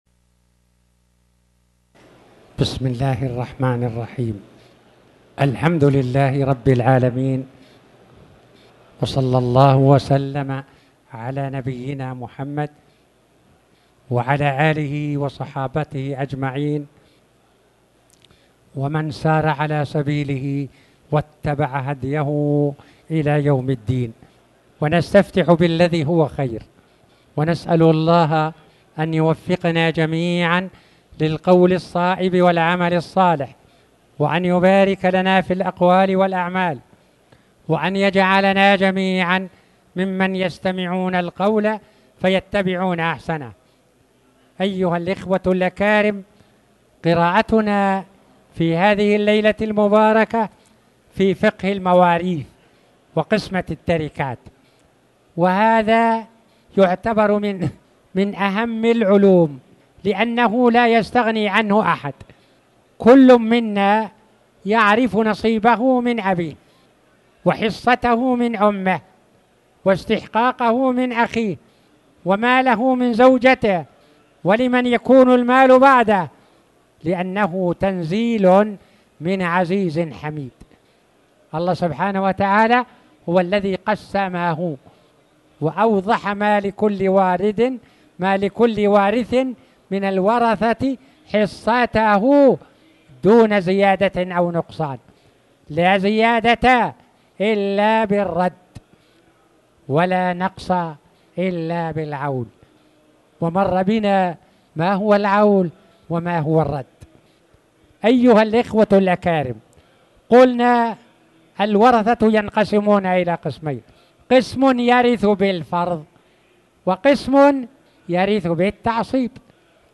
تاريخ النشر ٢٦ جمادى الأولى ١٤٣٩ هـ المكان: المسجد الحرام الشيخ